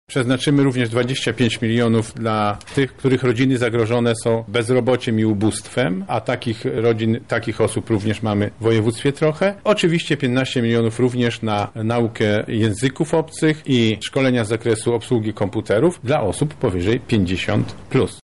O szczegółach mówi marszałek województwa Sławomir Sosnowski.